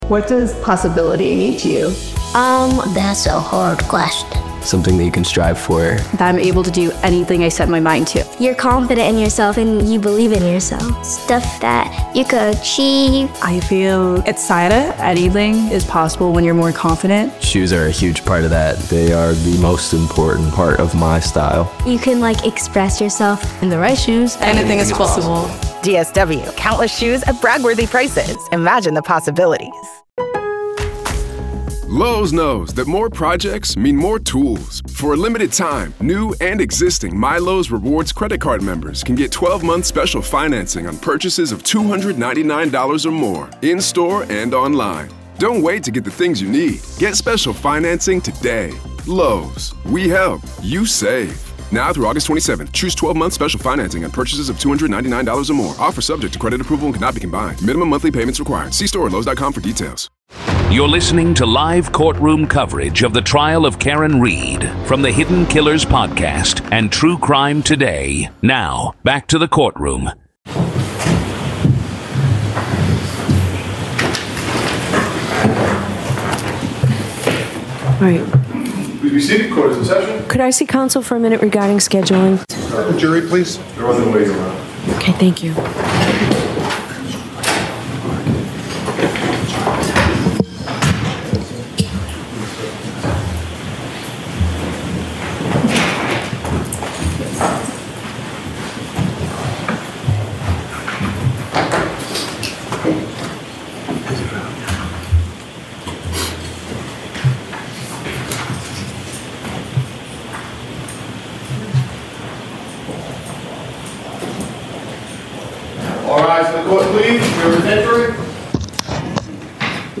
This is audio from the courtroom in the high-profile murder retrial